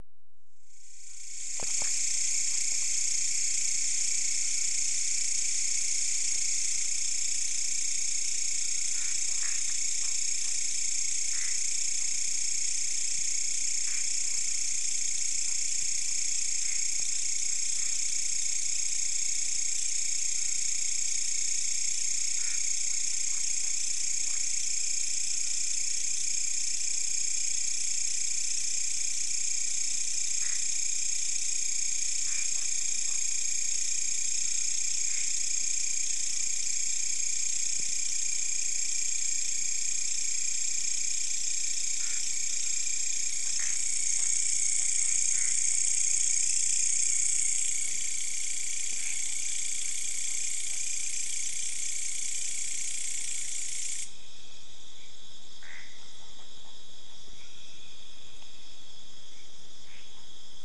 In this jungle we heard an interesting insect, I assume. We never saw the creature but its sound was really loud and very easy to follow. You could hear it coming from far away, closing in and then going further again.
the recorded sound seems to be cicada =)))
The strangest thing is that we never saw them although the source of the sound was clearly moving.
jungle.ogg